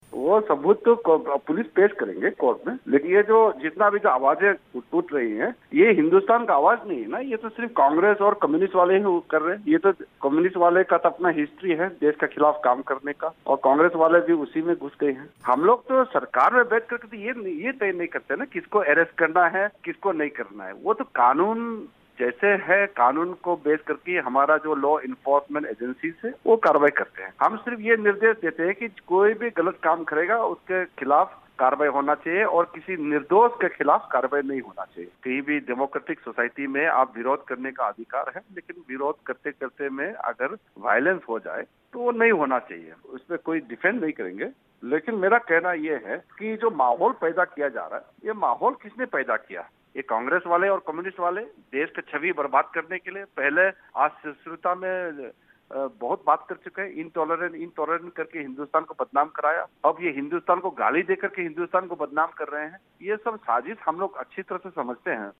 छात्र नेता कन्हैया कुमार की गिरफ्तारी और जेएनयू विवाद पर उठे सवालों के बारे में केंद्रीय गृह राज्यमंत्री किरण रिजिजू ने कहा कि ये विपक्षी पार्टियों की साज़िश है.